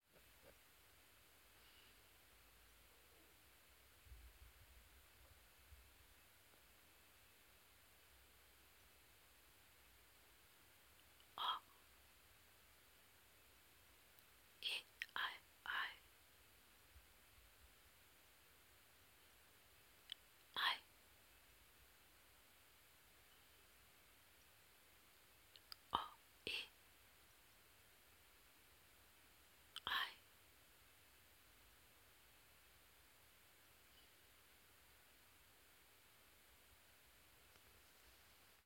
This interpretation was created for my own notebook derived from the score. Performed by the composer in her home, 9 January 2017.